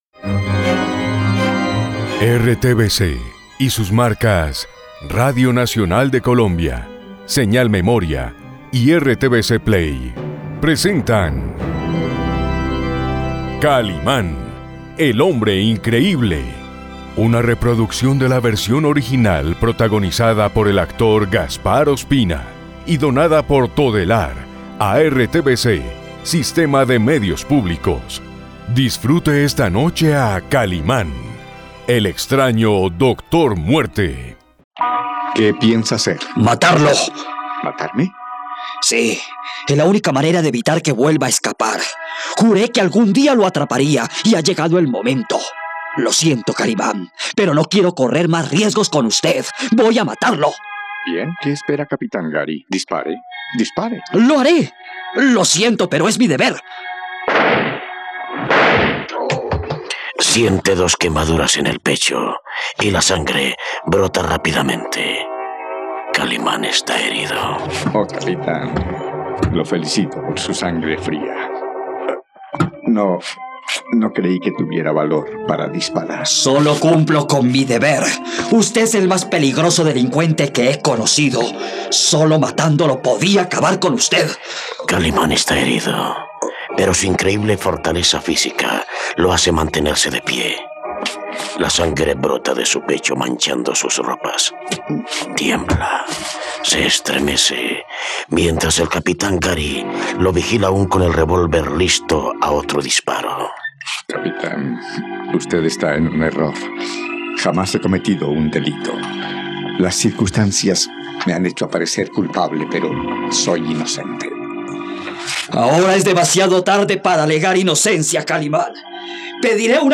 ..Radionovela. Gary descubre al auténtico Kalimán y dispara sin vacilar dos tiros directos a su pecho.